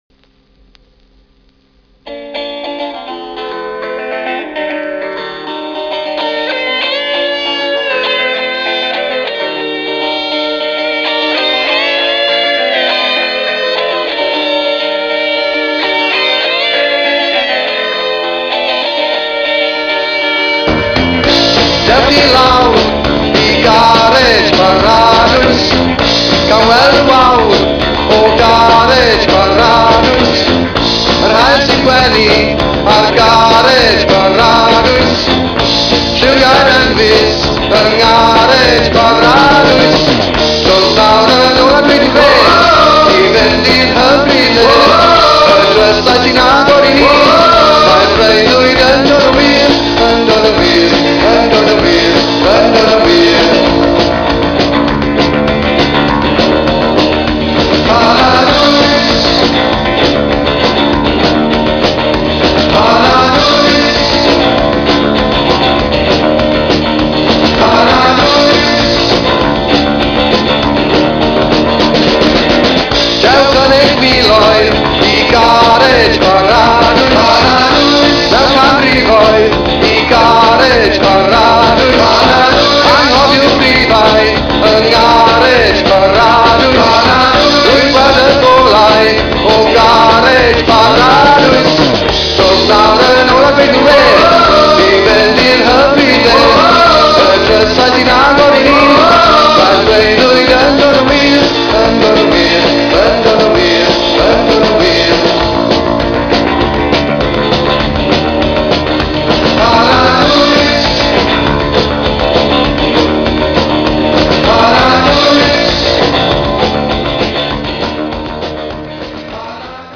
power pop bandの4th single。